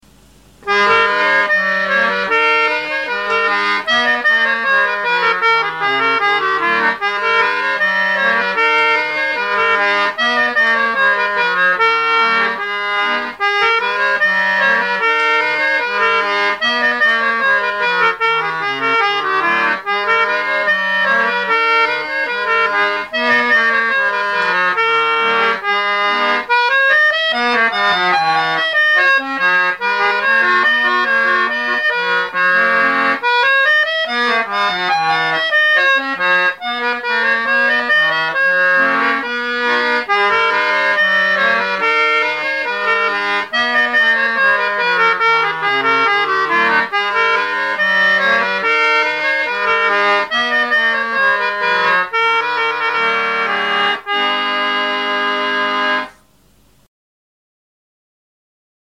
all "single take" onto a mini Sanyo cassette recorder
are played on a 48 button ebony ended New Model Lachenal